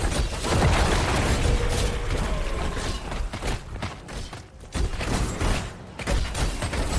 Download Fierce Fight sound effect for free.
Fierce Fight